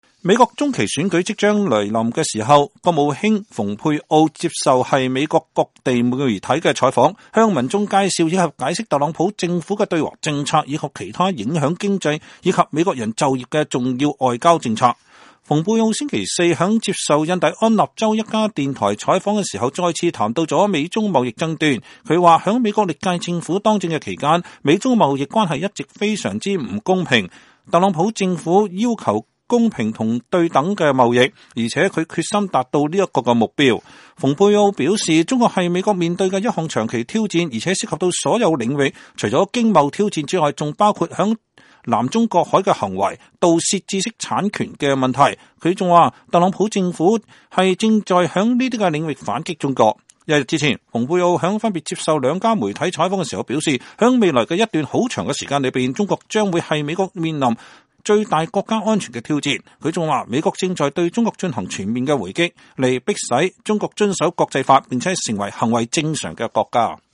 蓬佩奧星期四（2018年11月1日）在接受印第安納州一家電台採訪時再次談到美中貿易爭端。他說，在美國歷屆政府當政期間，美中貿易關係一直“非常、非常”不公平，但特朗普政府要求公平與對等貿易，而且他決心達至這一目標。